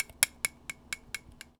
R - Foley 230.wav